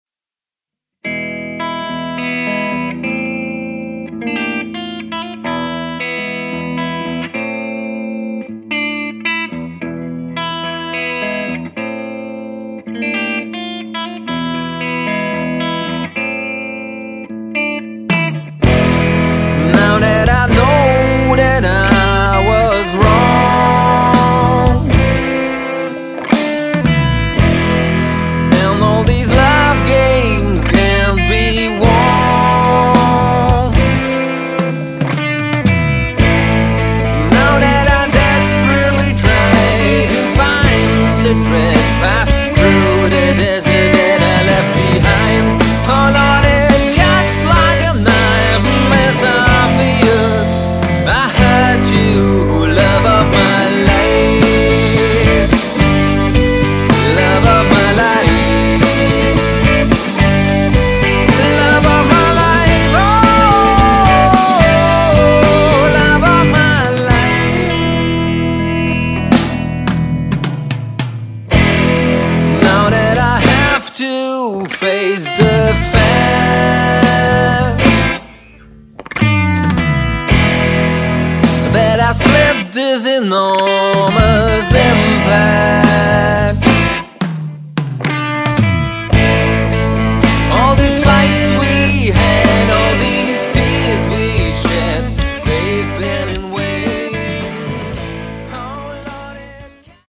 Singer-Songwriter Pop Songs